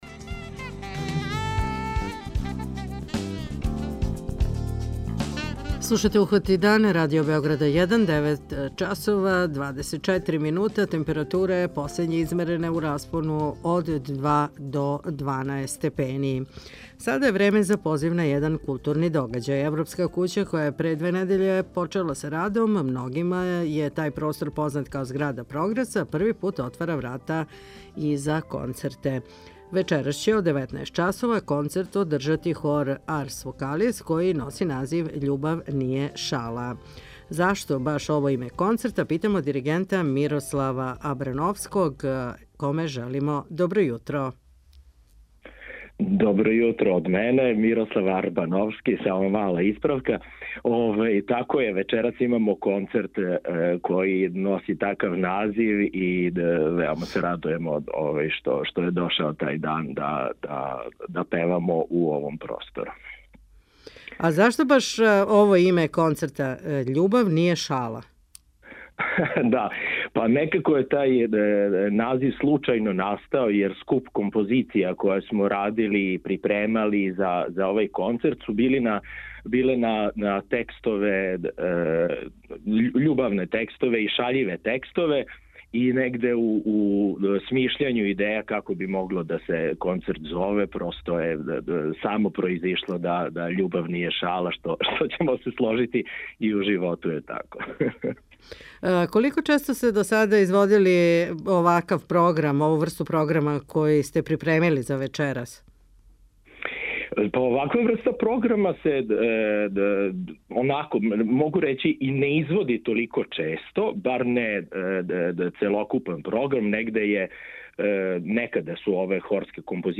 Uhvati dan - Radio emisija 30.09.2024.